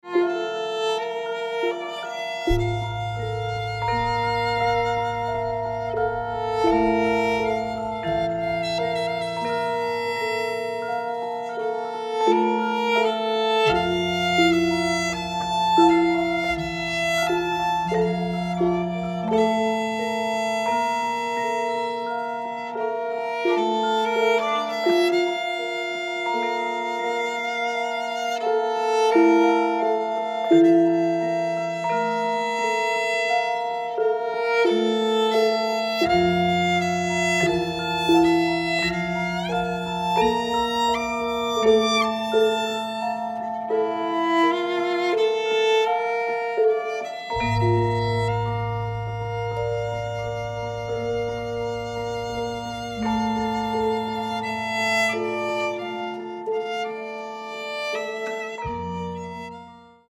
Violin and Javanese gamelan (pelog)
violin .